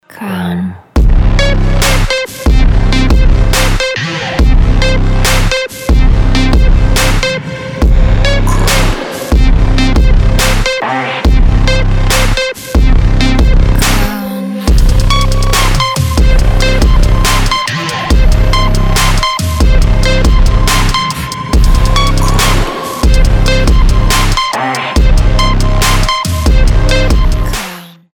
• Качество: 320, Stereo
мощные басы
качающие
Трэп звонок